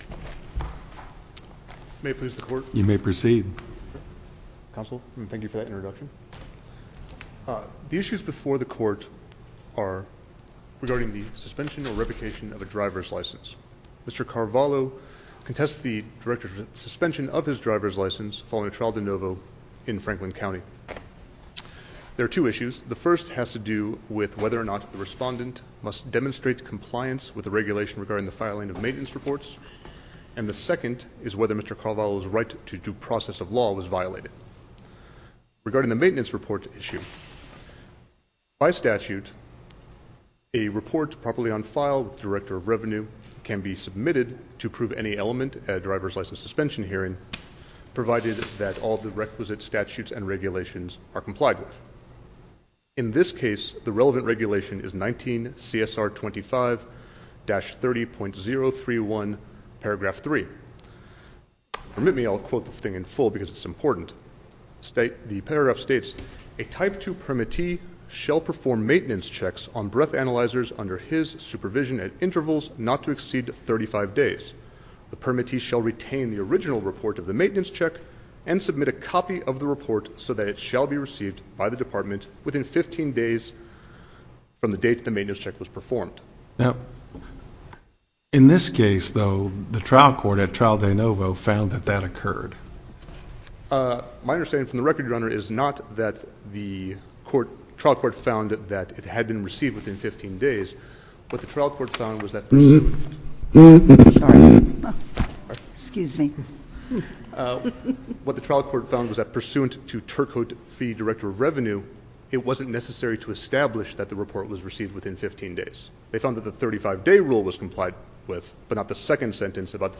link to MP3 audio file of oral arguments in SC97229
Judge Thomas N. Chapman – a judge on the Missouri Court of Appeals, Western District – sat in this case by special designation in place of Judge Patricia Breckenridge.